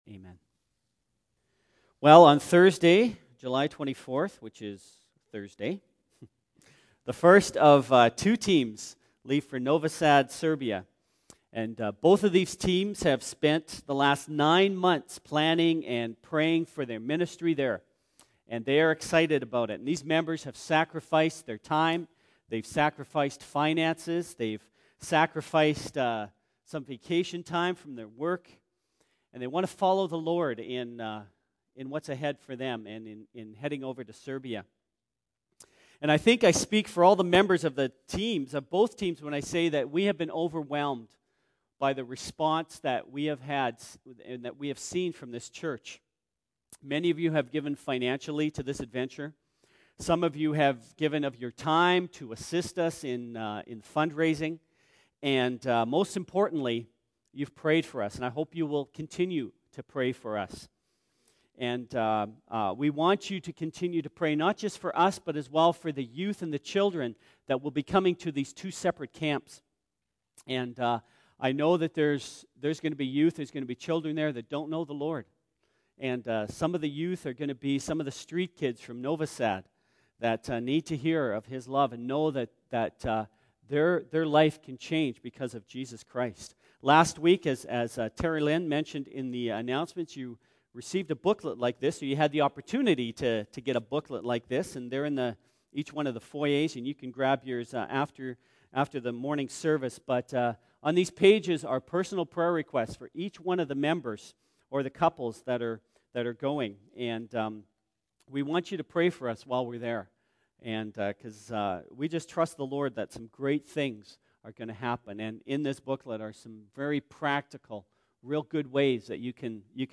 Sermon Archives Jul 20